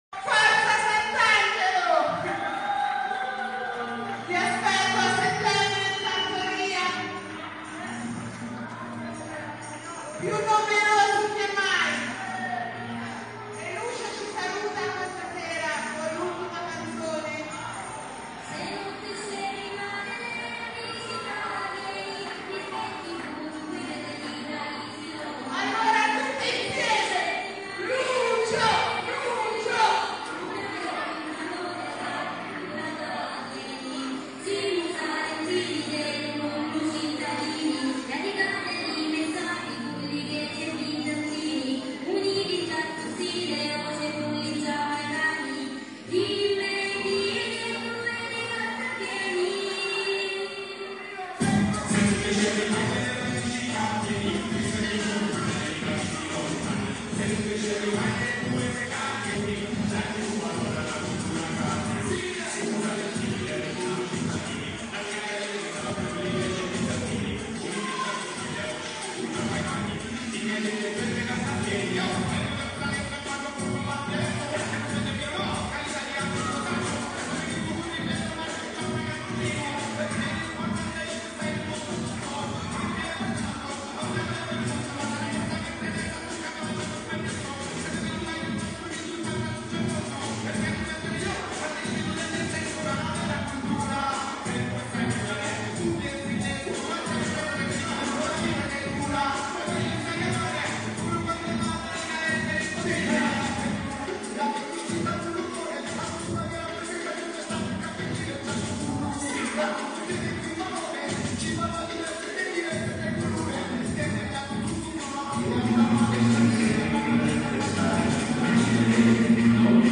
Questo è quello che è accaduto nella serata di sabato in Piazza Umberto I, in pieno centro storico. Giovani, ragazzi e adulti di ogni età hanno animato il rione fino a tarda notte con musica, canti, balli e danza di ogni tipo.
musica a tutto volume fino alle 2.30